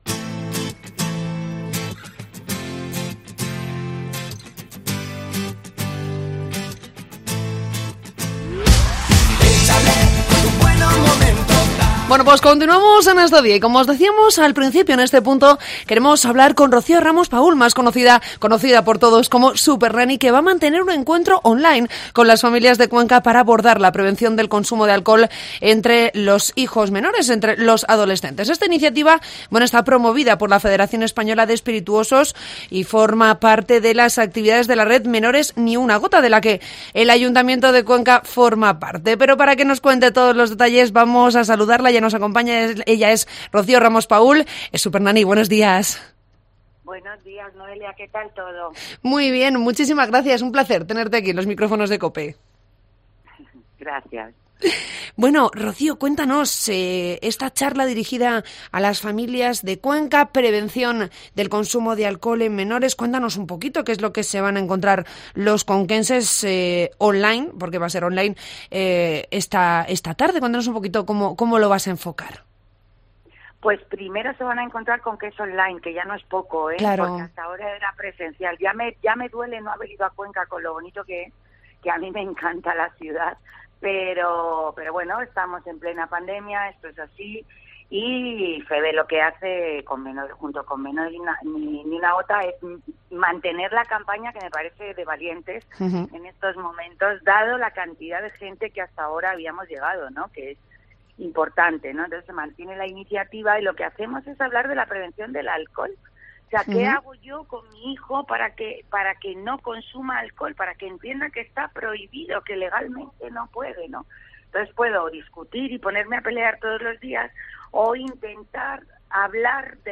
Entrevista con la psicóloga Rocío Ramos-Paúl, Supernanny